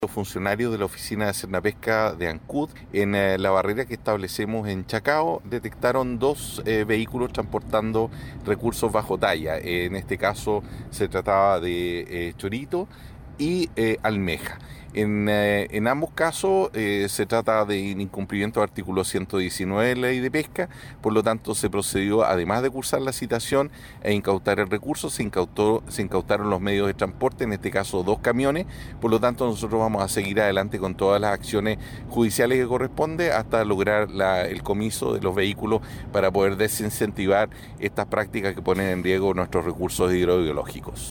Los funcionarios en conjunto con Carabineros detectaron dos camiones  que transportaban casi dos toneladas de recursos bajo la talla mínima legal, indicó el director regional del Sernapesca, Eduardo Aguilera.